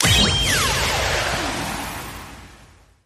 Feature_Buy_Start_Sound.mp3